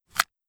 pickUp.wav